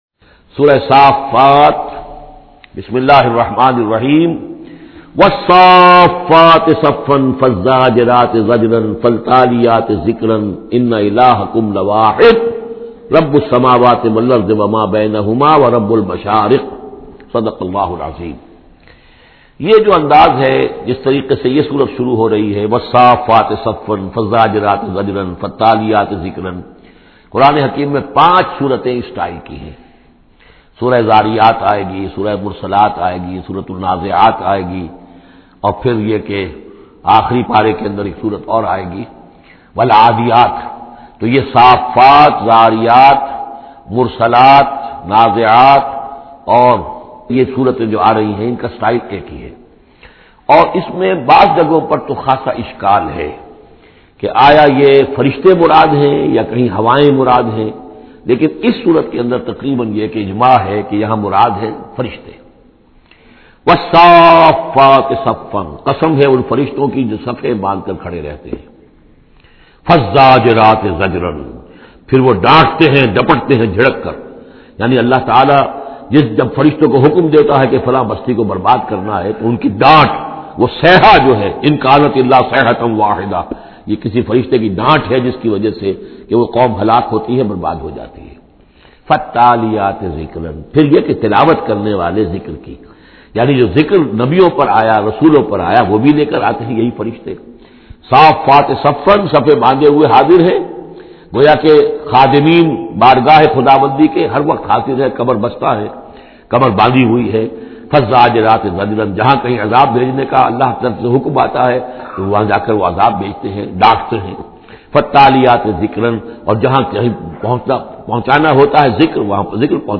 Listen this tafseer in the voice of Dr Israr Ahmed.